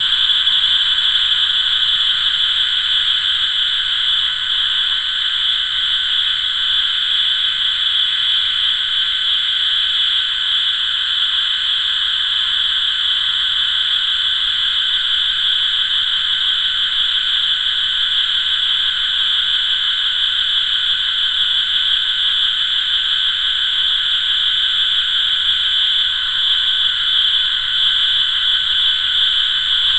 Donation Sound Effects - Free AI Generator & Downloads